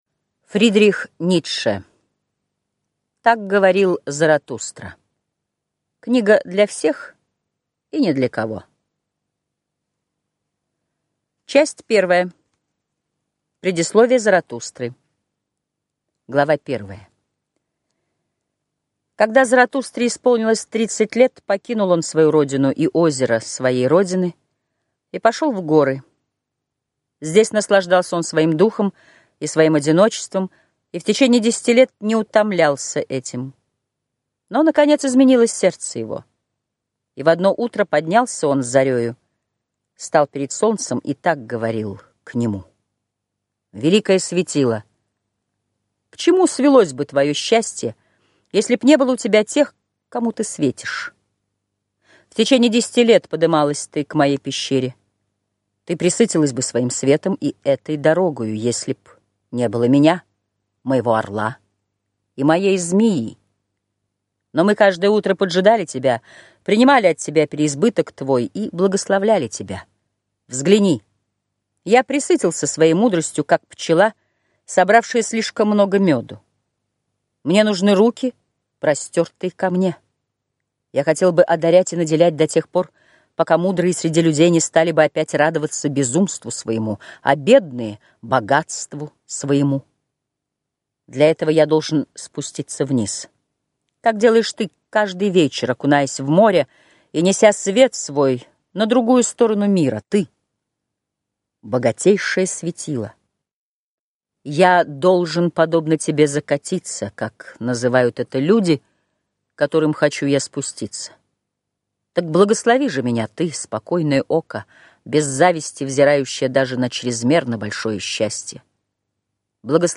Аудиокнига Так говорил Заратустра | Библиотека аудиокниг